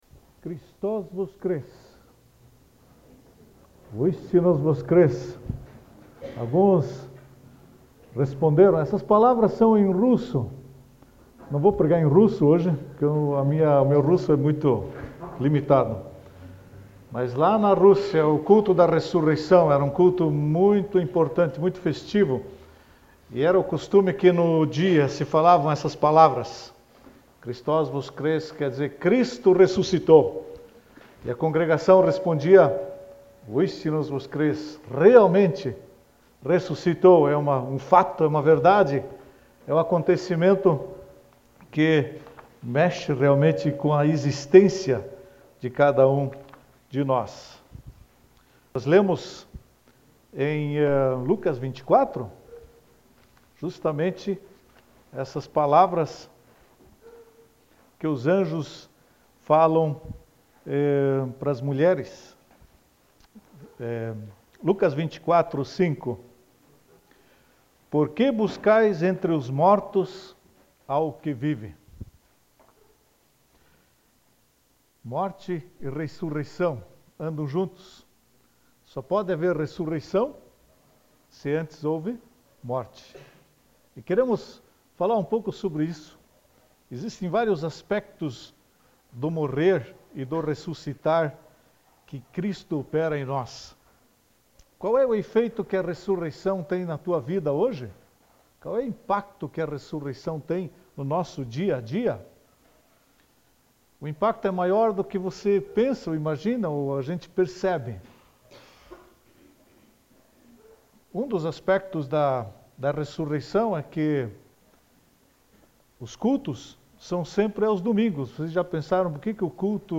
no domingo de Páscoa